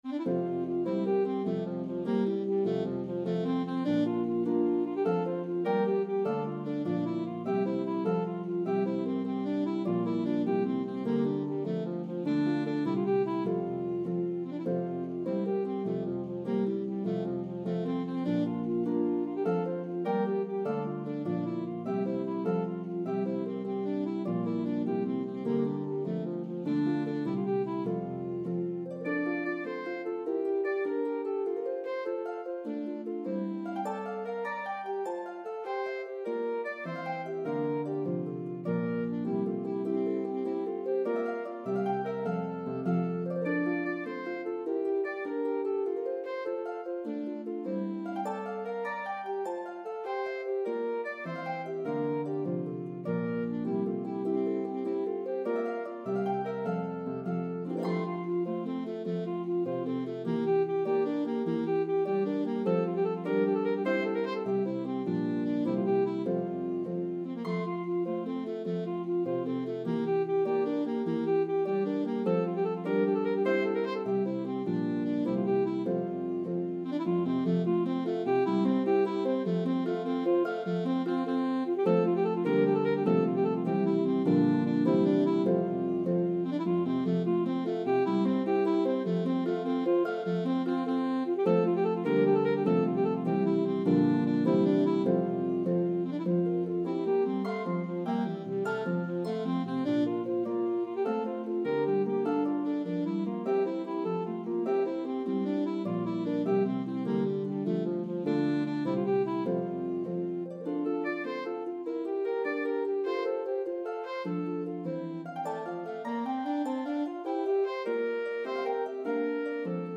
A spirited Irish Jig